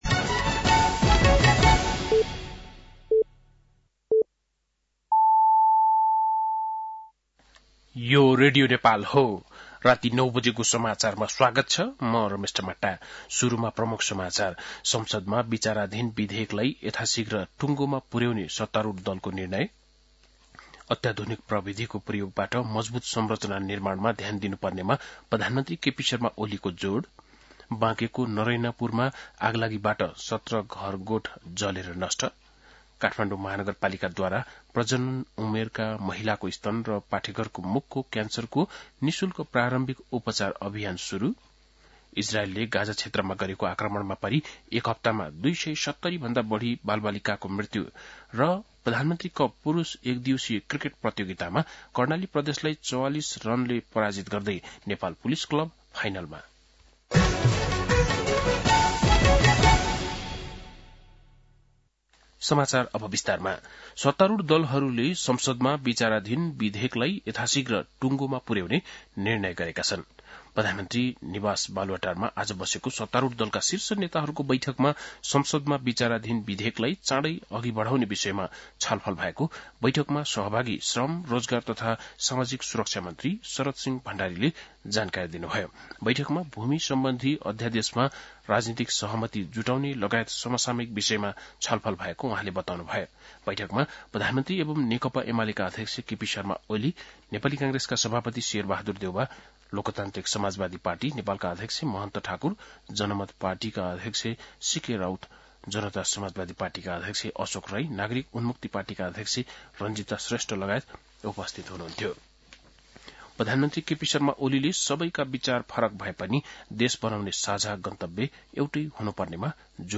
बेलुकी ९ बजेको नेपाली समाचार : १२ चैत , २०८१
9-PM-Nepali-NEWS-12-12.mp3